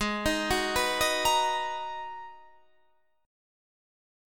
Abm9 chord